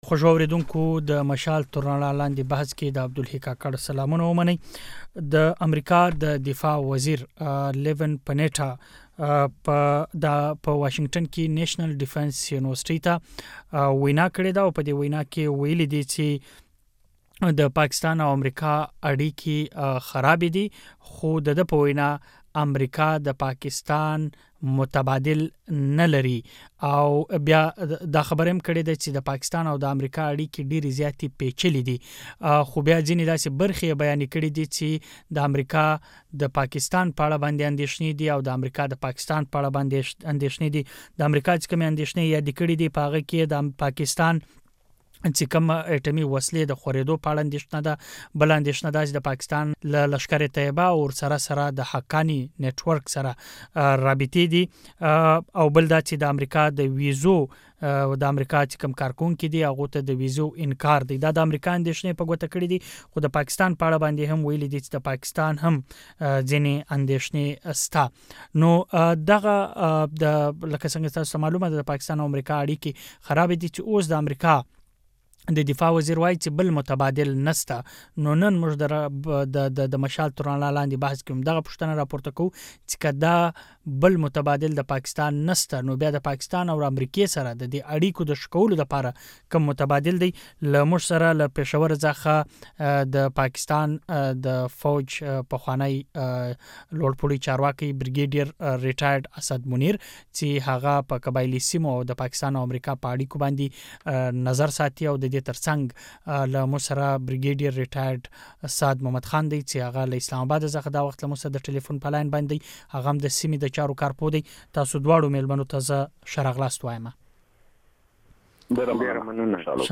د امريکا د دفاع وزير ليون پنيټا ويلي چي د نړیوالي ترهګري، د ايټمي وسلو خورولو د مخنیوي او په افغانستان کی د رول لوبولو په ترڅ کي د پاکستان متبادل نه ويني. خو اوس چی امريکا په رسمي توګه ويلي چي له پاکستان سره د اړیکو ساتلو پرته بله لار نشته نو دا پوښتنه راپورته کيږي چي که دواړه هيوادونه په يو بل دومري تکيه لري نو بيا څنګه کولی شي د يو بل انديښنی له مينځه يوشي. هم دا او ورسره تړلي نوري پوښتني د مشال تر رڼا لاندي بحث کي